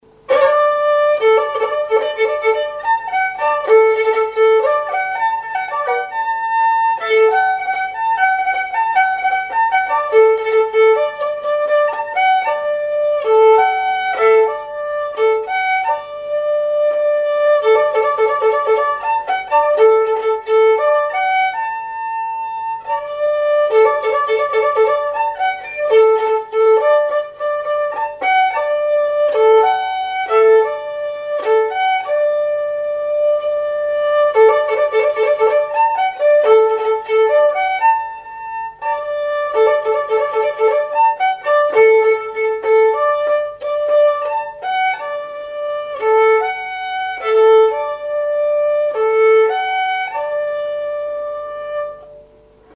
Genre brève
Auto-enregistrement
Pièce musicale inédite